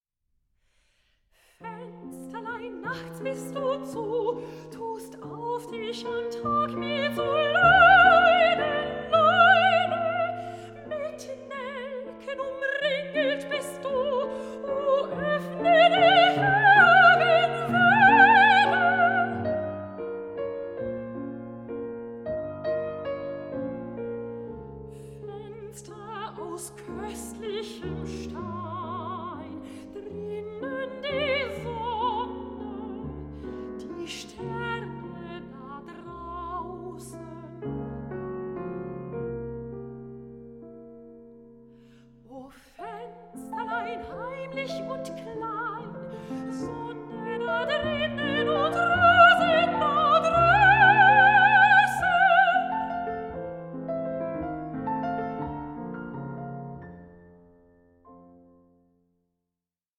GERMAN AND FRENCH SONGS ON LOVE AND LOSS